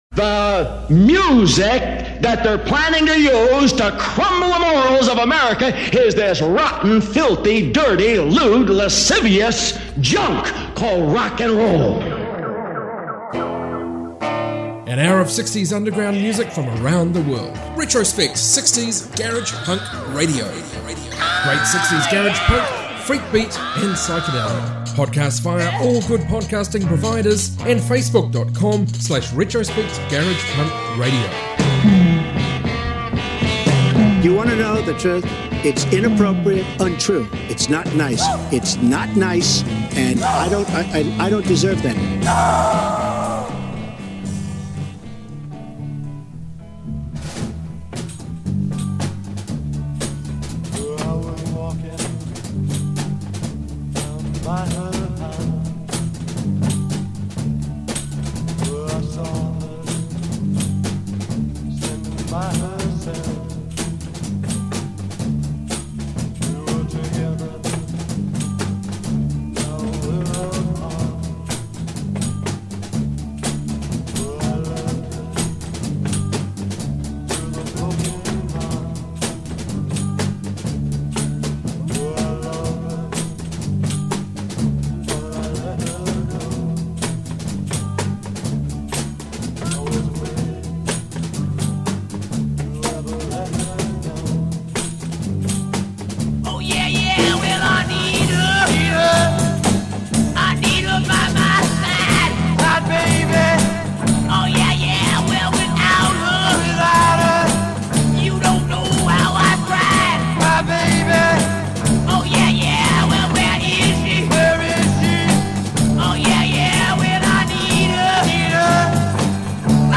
60s global garage rock!